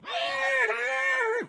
animalia_horse_death.ogg